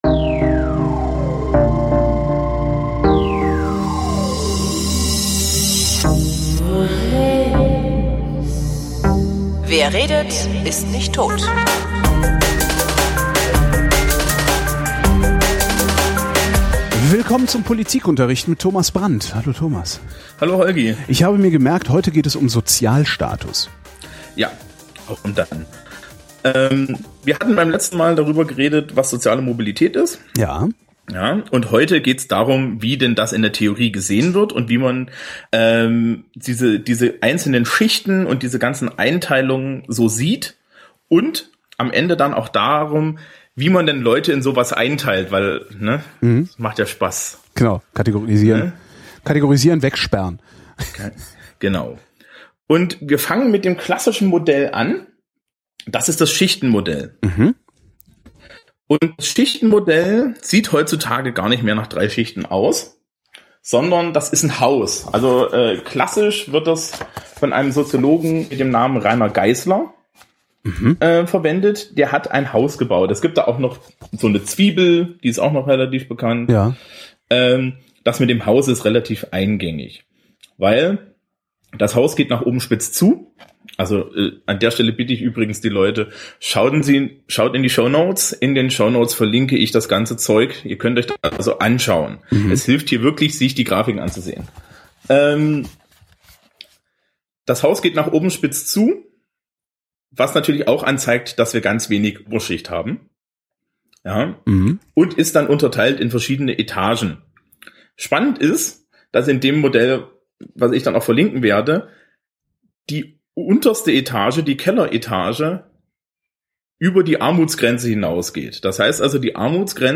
Auch diese Folge ist von minderer Klangqualität.